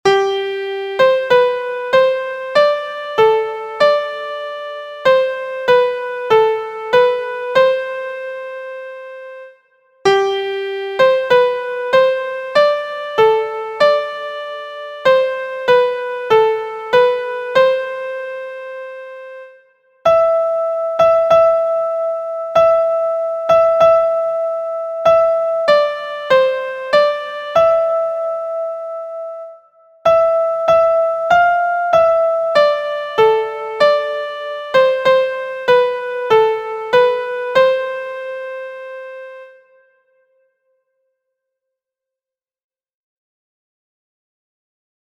Each phrase begins and ends with a tonic pitch, the interval Re\La/Re at mid-phrase hints at a minor tonality.
• Origin: USA – Folk Song – cir. 1861
• Key: F Major
• Time: 4/4